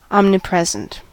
omnipresent: Wikimedia Commons US English Pronunciations
En-us-omnipresent.WAV